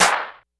Snare_15.wav